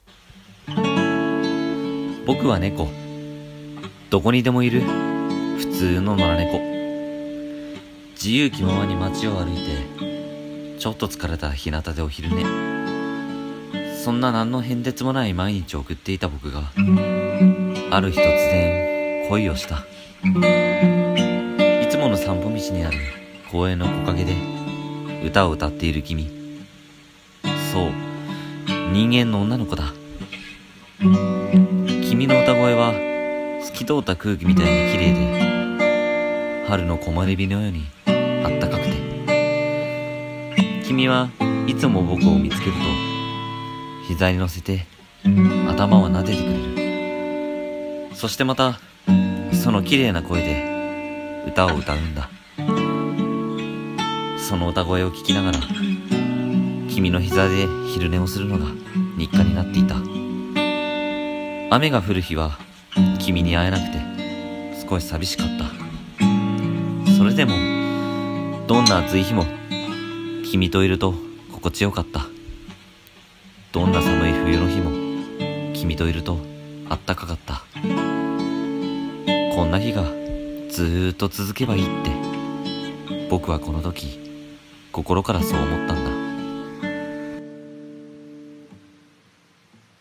【朗読劇】ボクは猫 前編 (コラボ用)